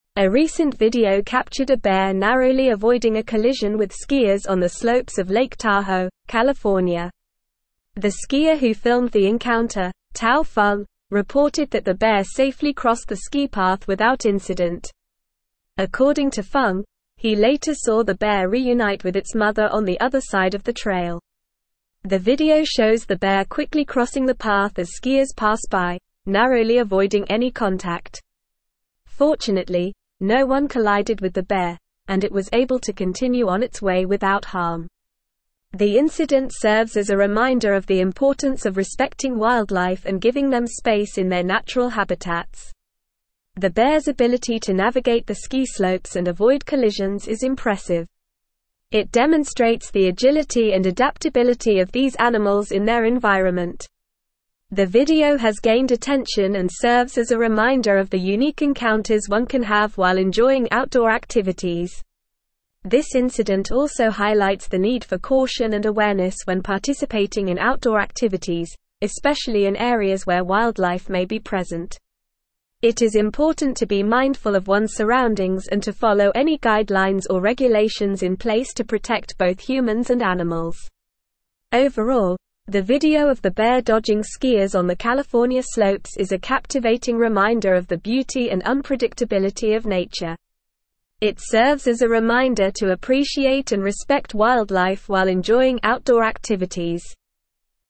Normal
English-Newsroom-Advanced-NORMAL-Reading-Skier-narrowly-avoids-bear-collision-in-Lake-Tahoe.mp3